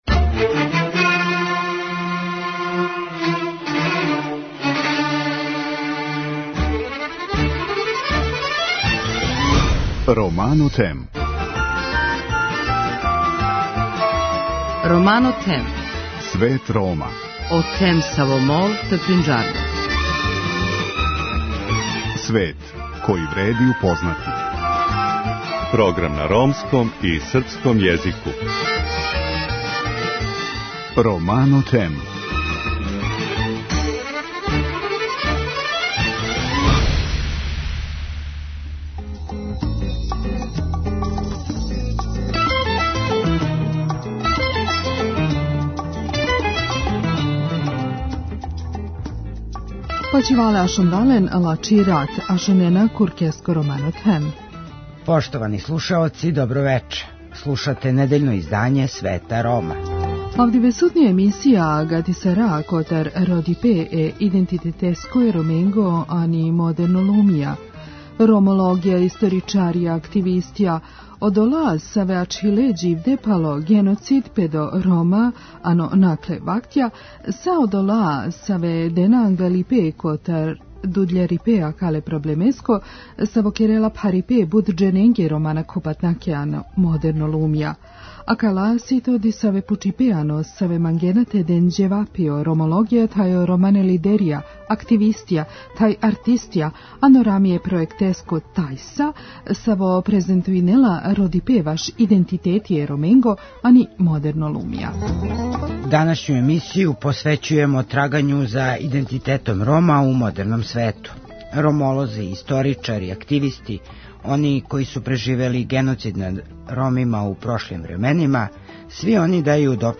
Рајко Ђурић је одржао говор током представљања „Граматике ромског језика” на Београдском сајму књига и том приликом истакао детаље геноцида над Ромима у Србији током Другог светског рата, али и у далекој прошлости, док су живели у својој прапостојбини Индији.